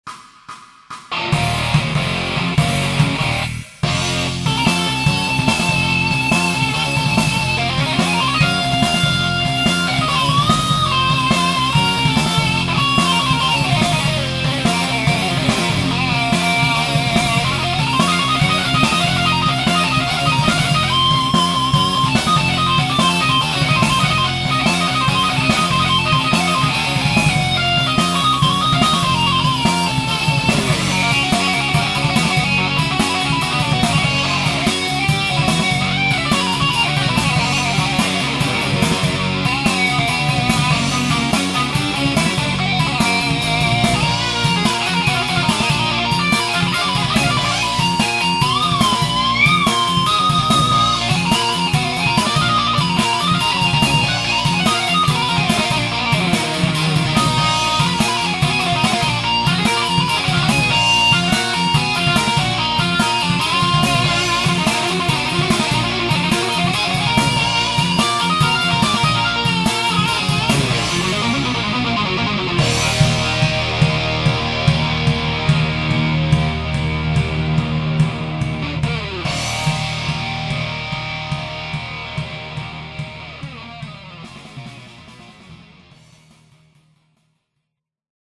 Metal 80's